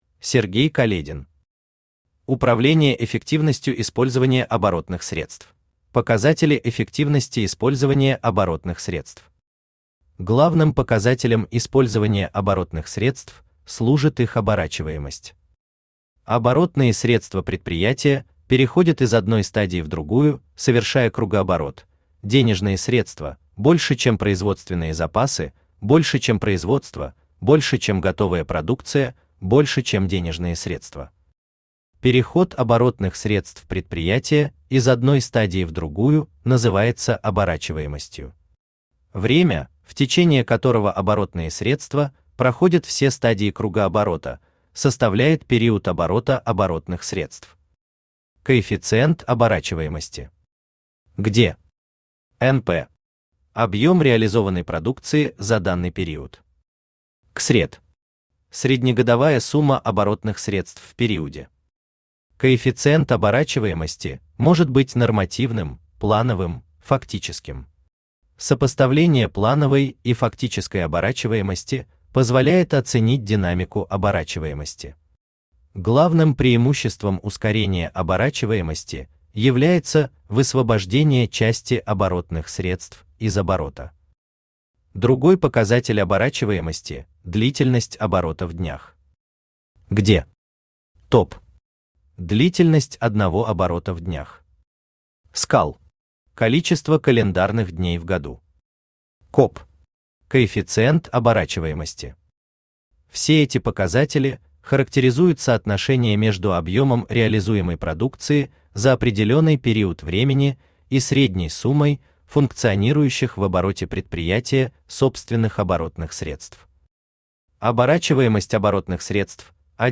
Aудиокнига Управление эффективностью использования оборотных средств Автор Сергей Каледин Читает аудиокнигу Авточтец ЛитРес.